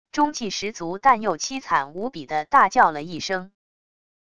中气十足但又凄惨无比的大叫了一声wav音频